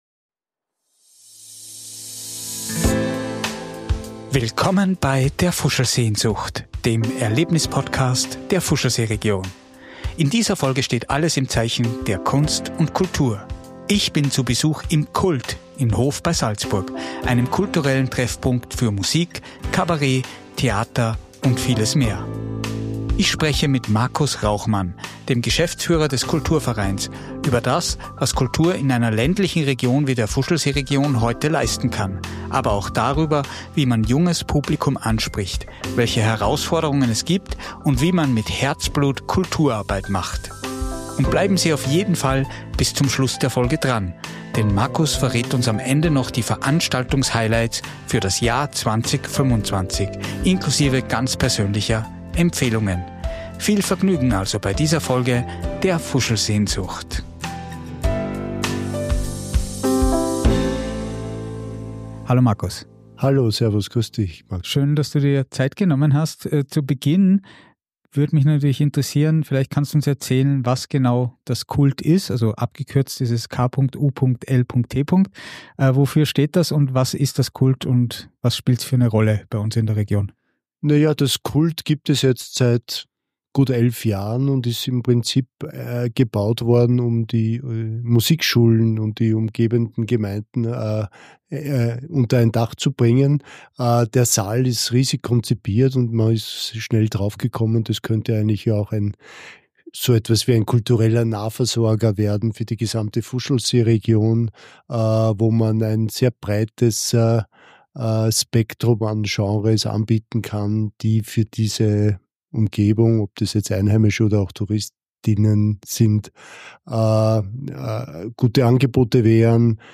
trifft die Protagonisten der verschiedenen Erlebnisse zum Interview